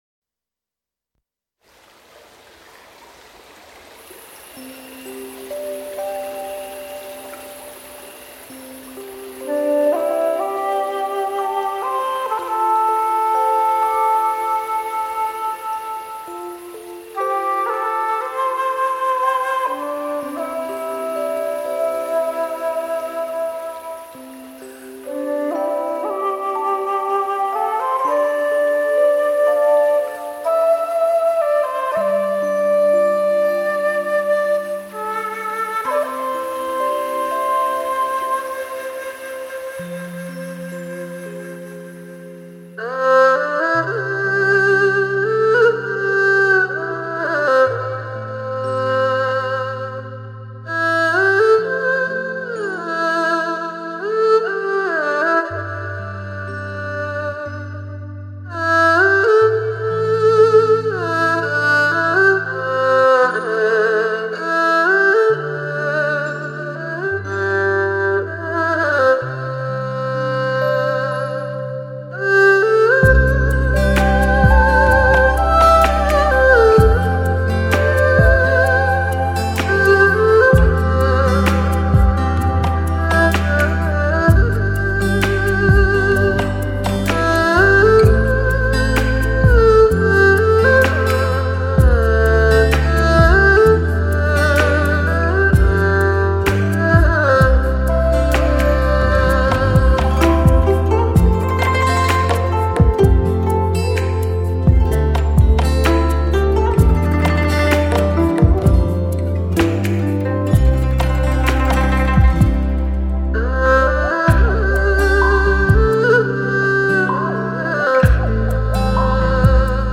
国内首次采用真空管U47LONG BODY咪、瑞士WEISS专业咪放大器、ADC模拟数码转换器等顶级器材录音。
全球首张中胡发烧天碟莅临东方。
中胡是民乐队中重要的中音乐器，音色浑厚，最善于演奏一些抒展、辽阔的歌唱性旋律。